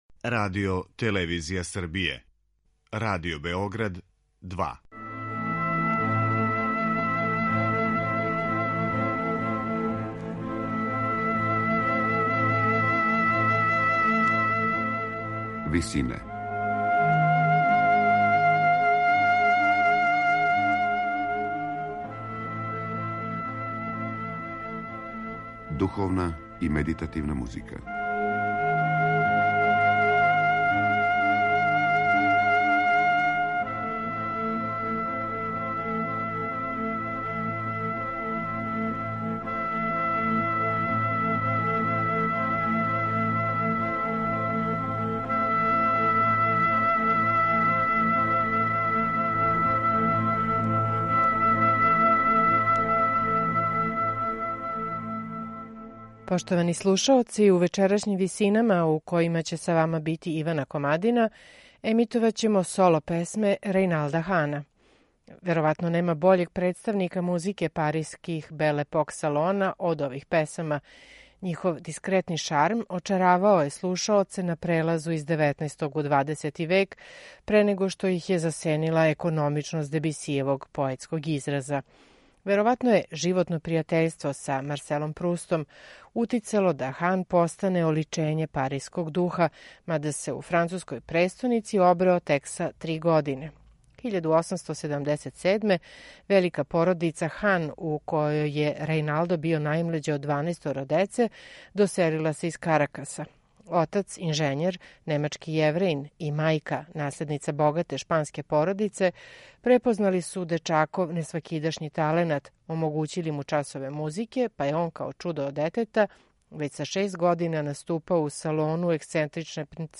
мецо-сопрана
пијанисте